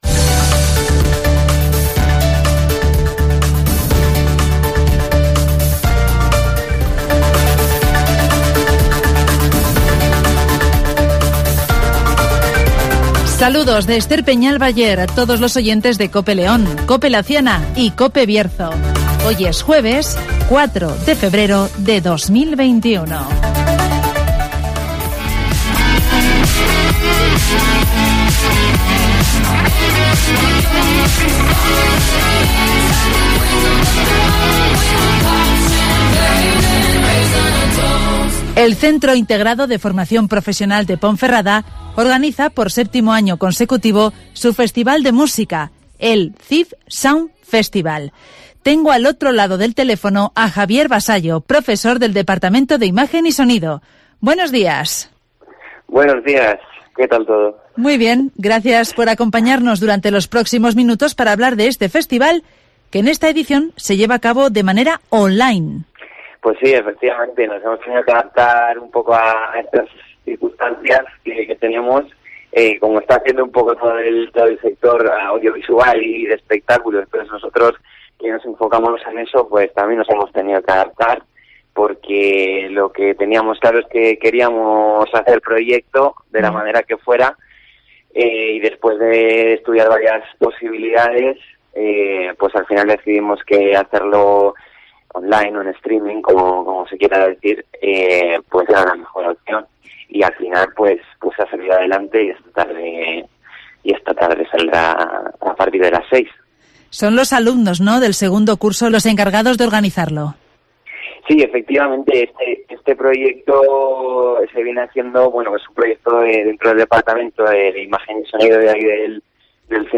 El festival de música del Cifp de Ponferrada se traslada a la red social Twitch (Entrevista